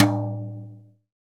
TOM SLAP T0J.wav